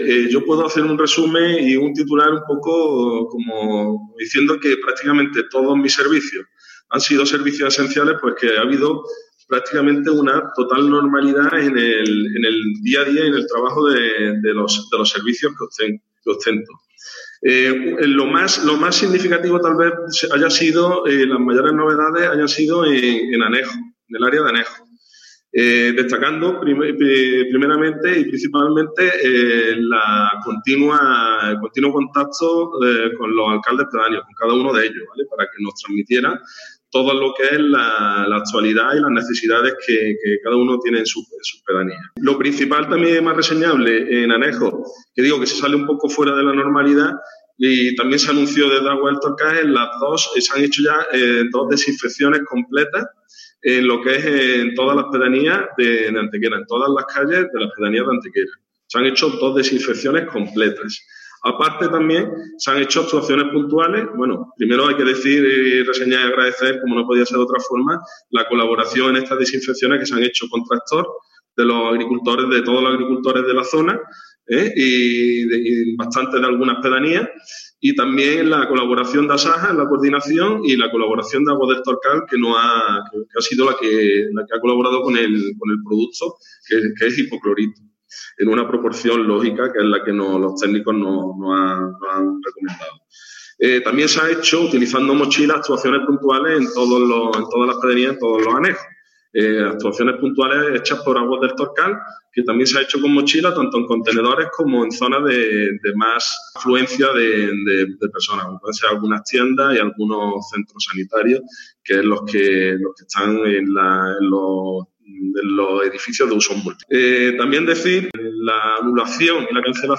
El alcalde de Antequera, Manolo Barón, y el concejal Juan Álvarez han desarrollado en la mañana de hoy una rueda de prensa telemática para la valoración del trabajo que se lleva realizando durante el Estado de Alarma en las áreas municipales de Anejos, Medio Ambiente y Electricidad.
Cortes de voz